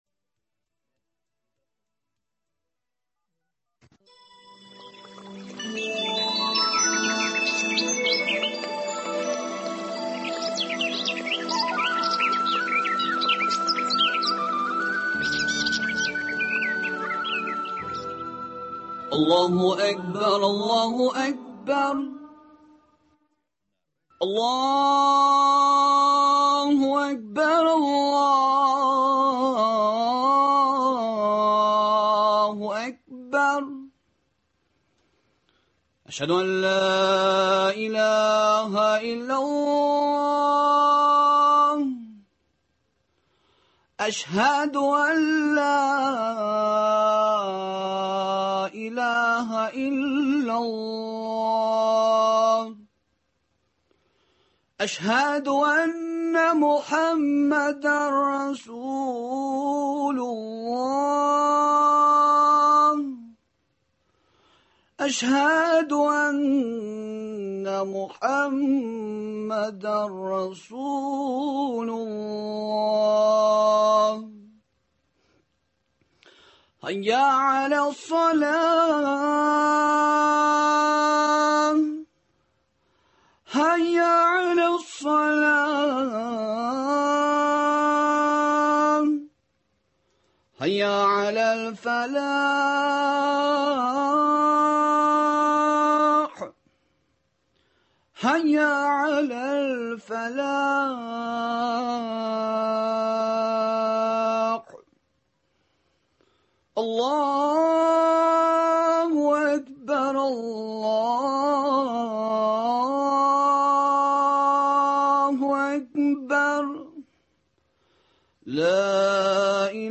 Турыдан-туры эфирда бара торган тапшыруда Республикабызның «Зәкәт» фонды белгечләре катнаша. Соңгы елларда инвалидларга һәм мохтаҗларга нинди ярдәм күрсәтелгән?